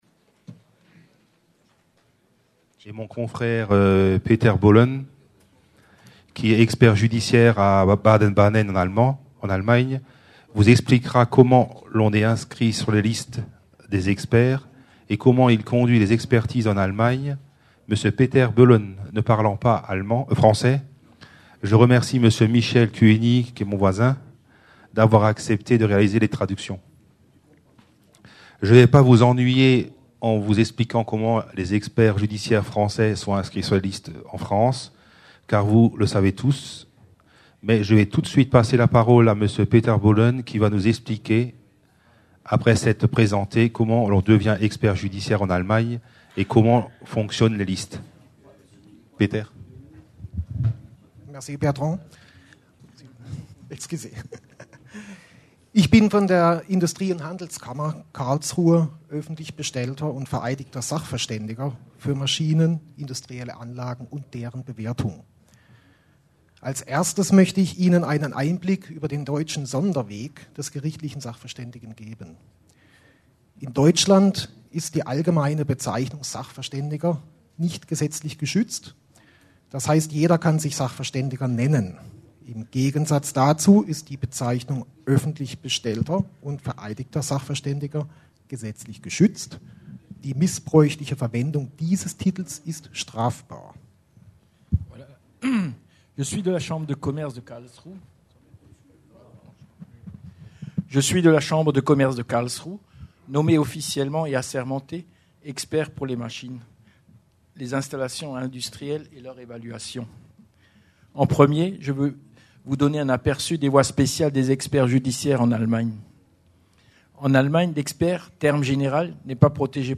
Colloque des Compagnies des Experts de Justice du Grand Est. Organisé par la Compagnie de Reims sous la présidence d’honneur de Monsieur le Premier Président et de Monsieur le Procureur Général de la Cour d’Appel de Reims. Titre : L'expertise en industrie et automatisme Intervenants